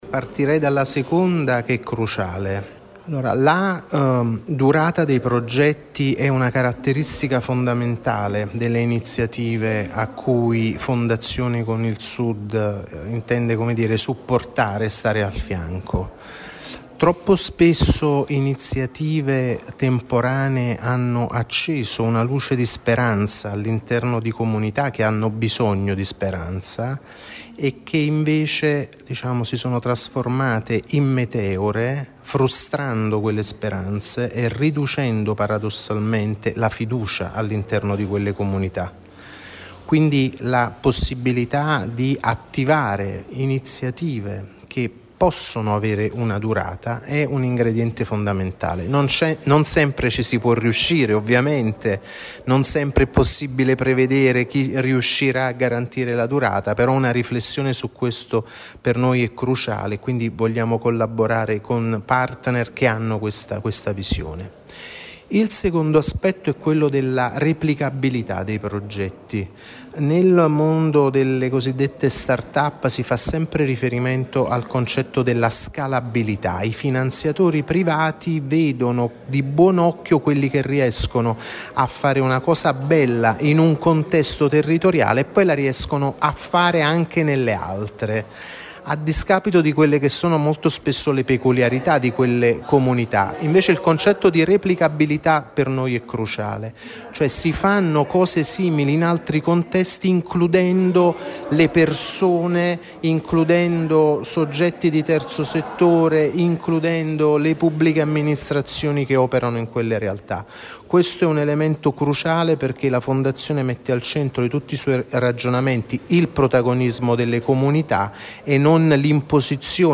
Caltanissetta sarà una delle città coinvolte nel progetto.